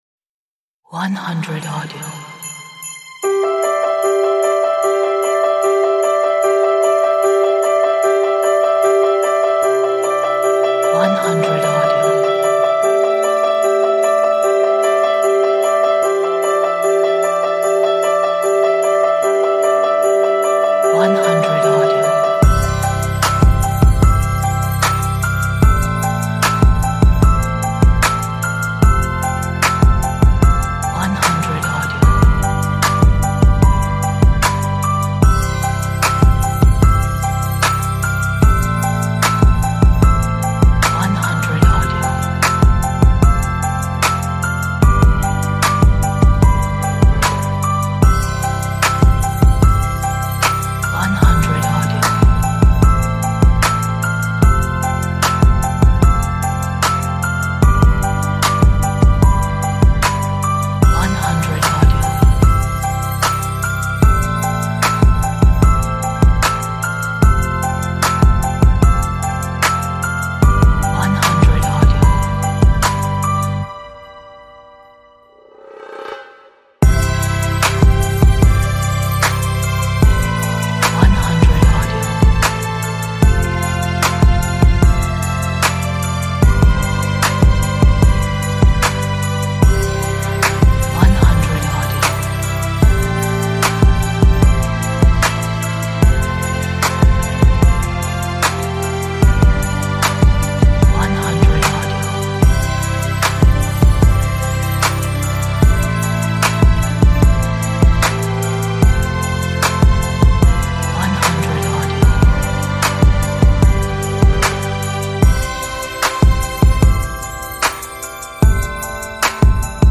a beautiful pop inspirational track
youtube videos 这是一首优美的流行歌曲 可用于tik tok、instagram、youtube视频